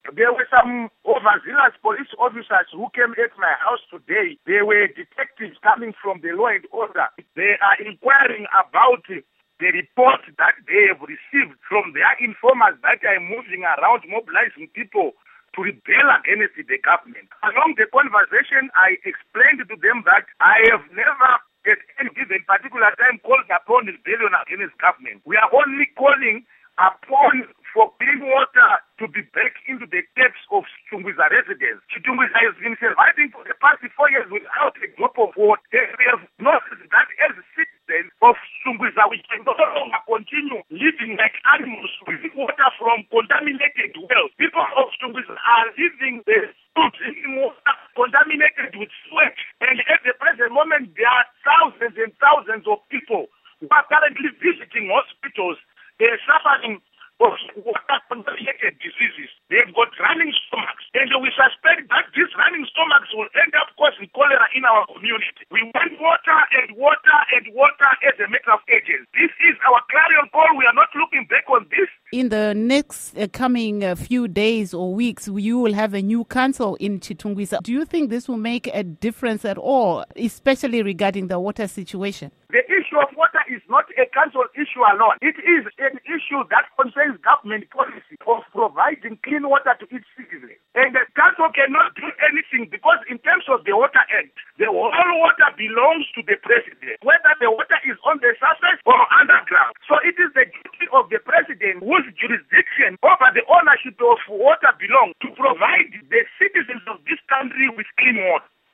Interview with Job Sikhala